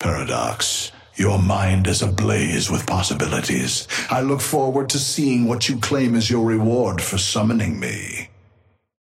Amber Hand voice line - Paradox, your mind is ablaze with possibilities.
Patron_male_ally_chrono_start_03.mp3